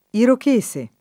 irochese
vai all'elenco alfabetico delle voci ingrandisci il carattere 100% rimpicciolisci il carattere stampa invia tramite posta elettronica codividi su Facebook irochese [ irok %S e ] (antiq. irocchese [ irokk %S e ]) etn.